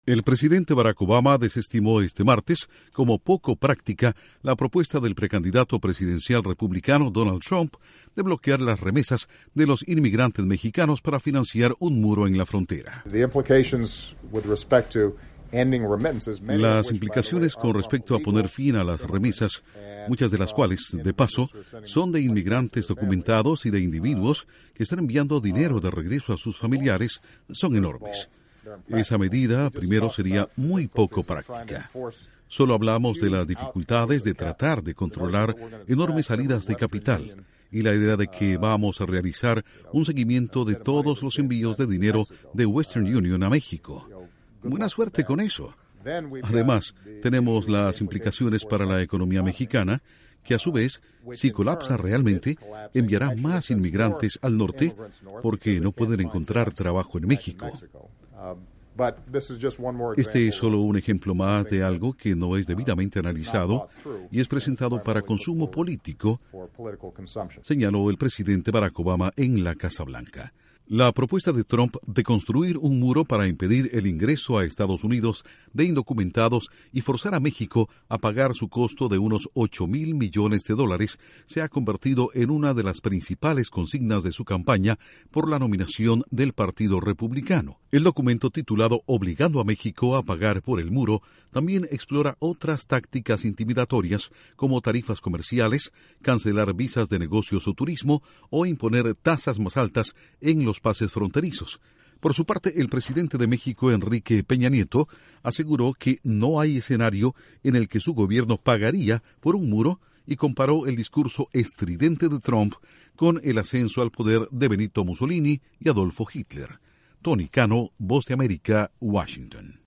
La Casa Blanca desestima propuesta de Trump para bloquear remesas hacia México. Informa desde la Voz de América en Washington